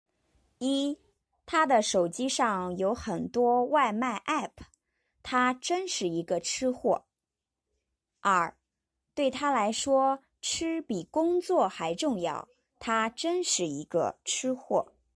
(chī huò)